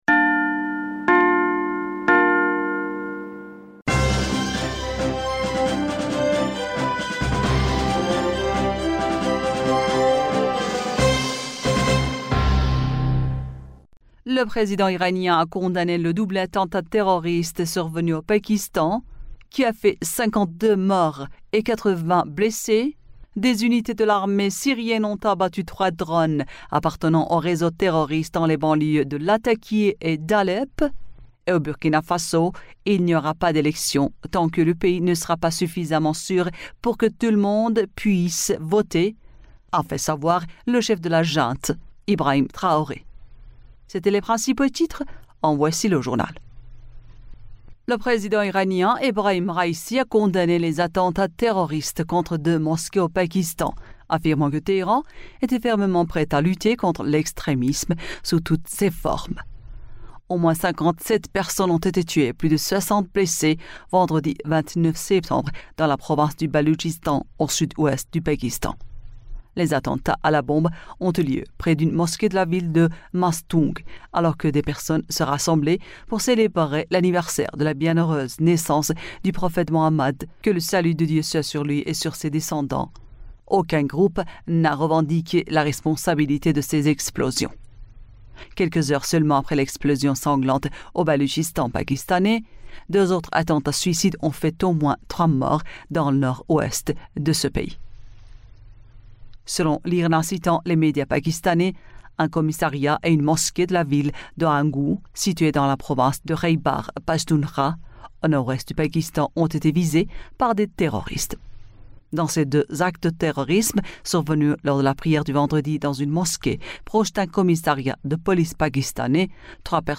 Bulletin d'information du 30 Septembre 2023